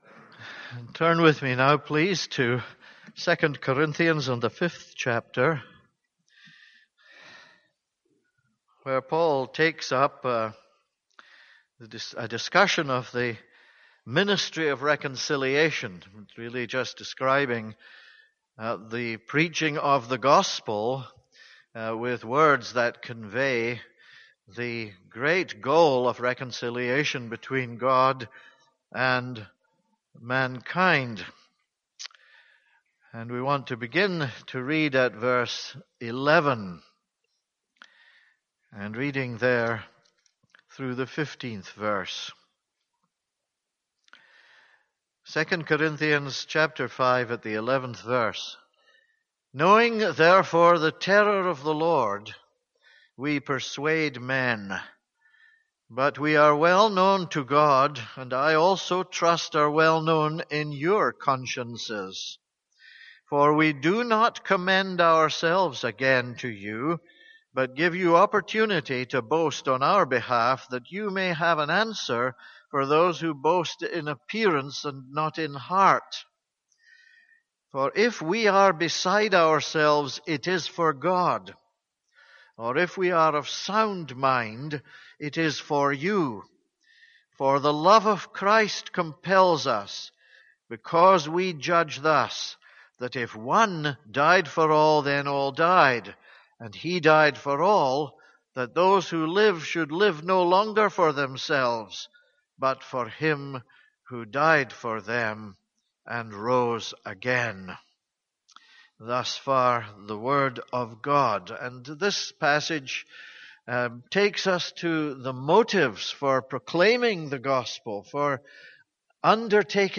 This is a sermon on 2 Corinthians 5:11-15.